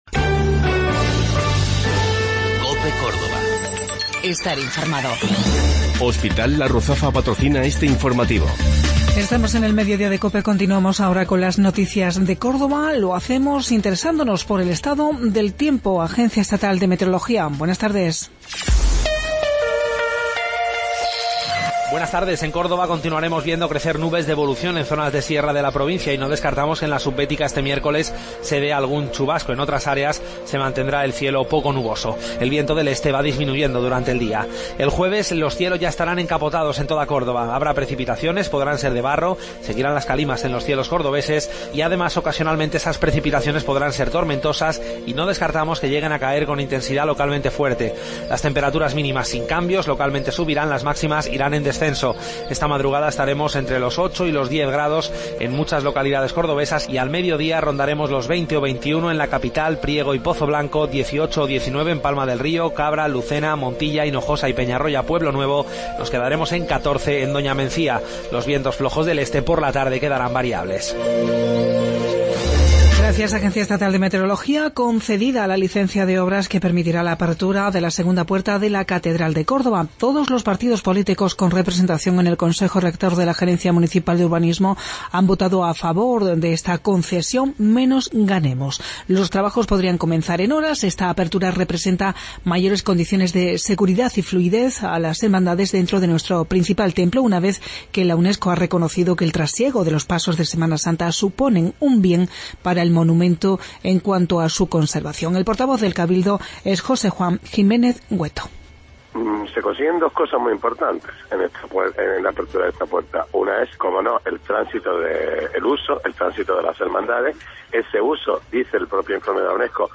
Mediodía en Cope. Informativo local 22 de Febrero 2017